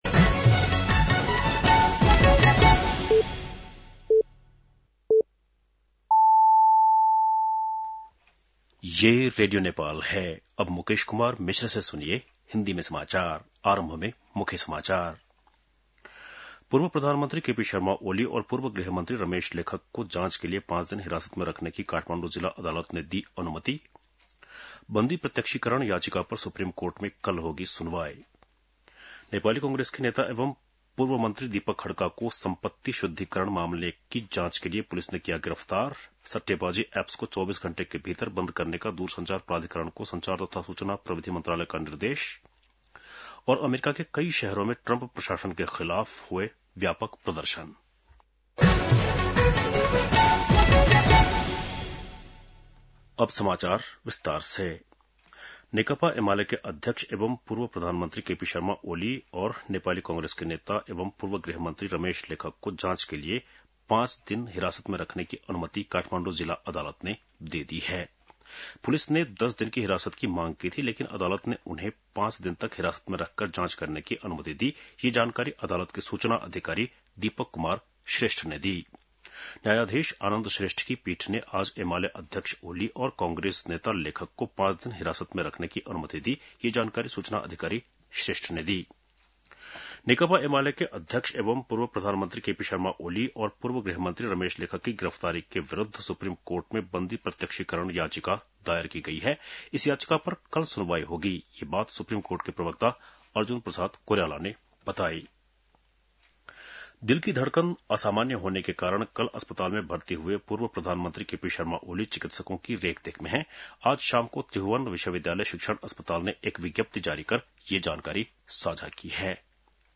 बेलुकी १० बजेको हिन्दी समाचार : १५ चैत , २०८२
10-pm-hindi-news.mp3